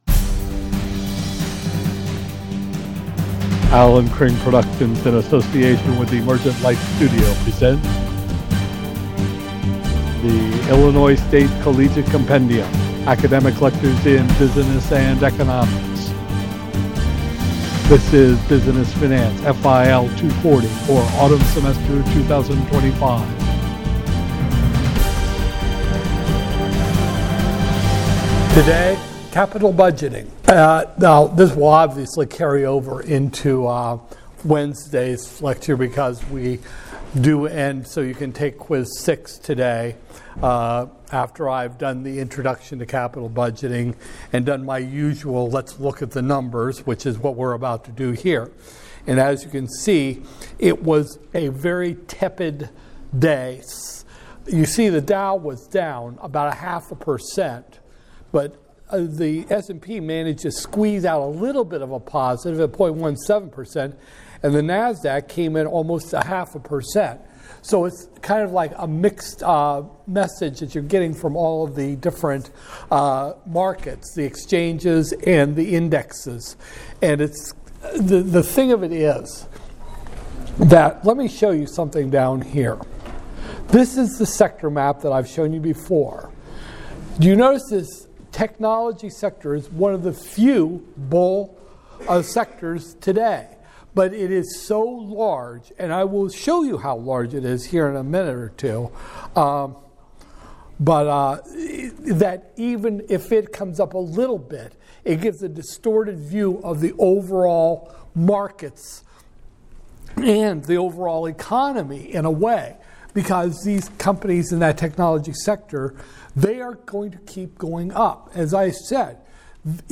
Business Finance, FIL 240-002, Spring 2025, Lecture 21